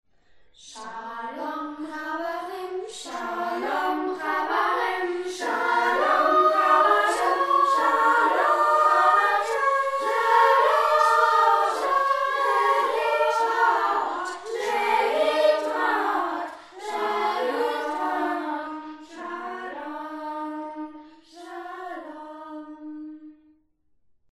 Das Lied wurde von einer 6. Klasse der Freien Waldorfschule Freiburg St. Georgen eingesungen.
Lied im Kanon Update needed